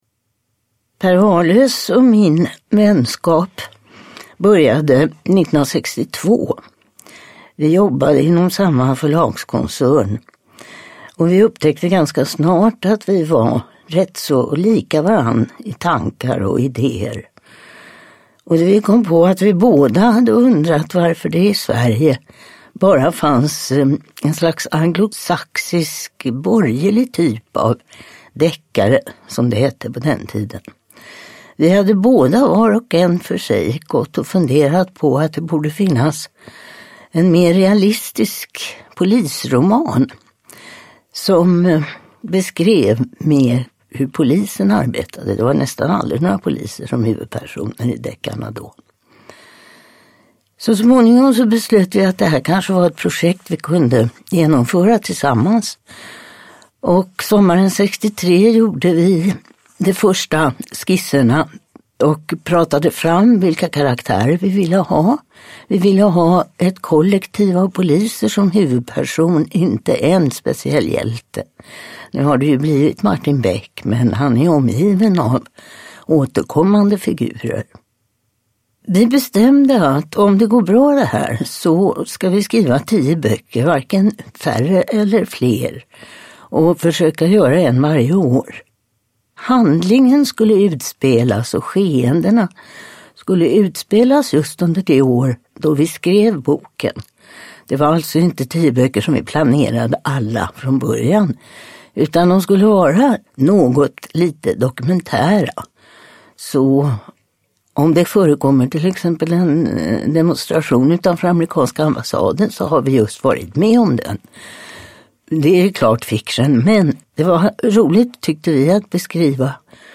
Mannen som gick upp i rök – Ljudbok – Laddas ner
Uppläsare: Torsten Wahlund